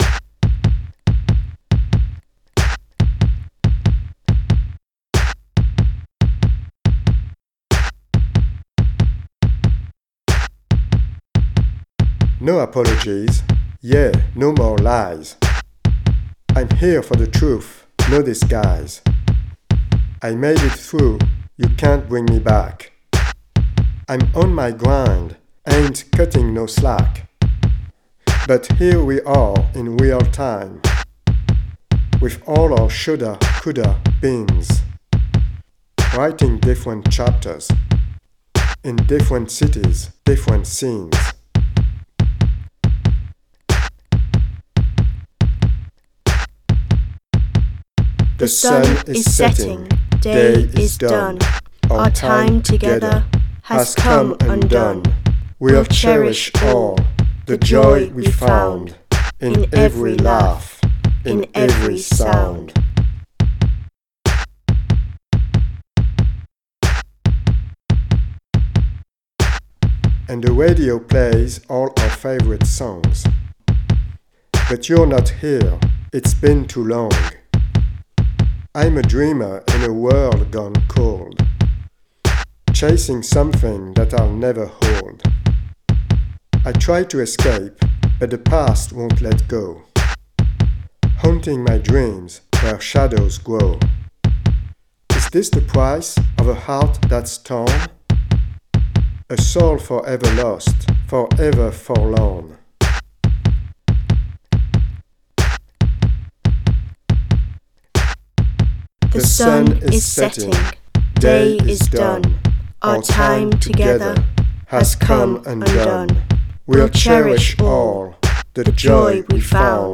drum machine
Chorus vocals on tracks 1